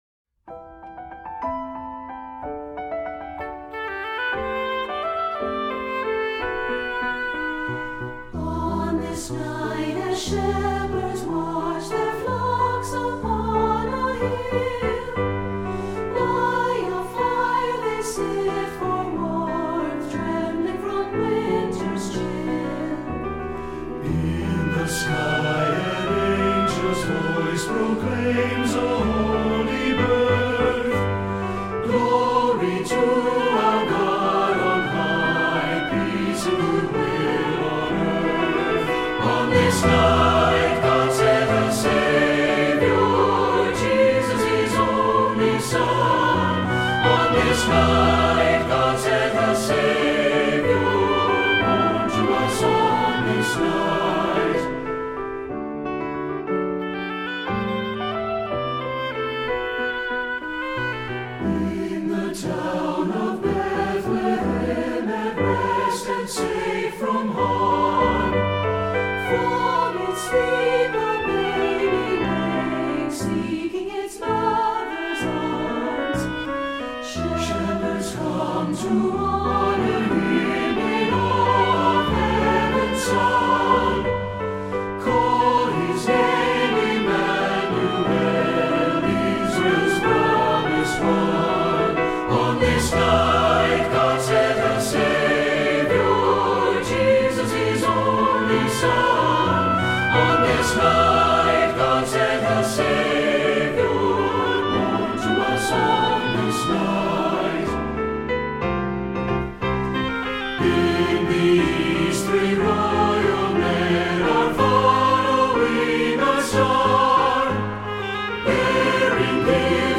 Voicing: SATB and Oboe